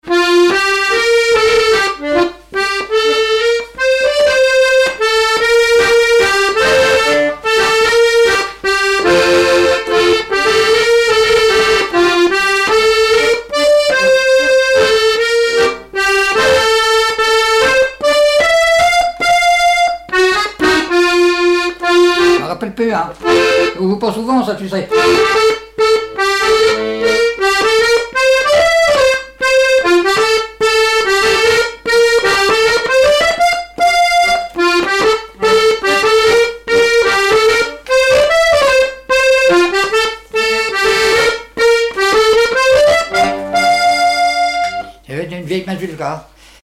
Monsireigne
danse : paskovia
accordéon chromatique
Pièce musicale inédite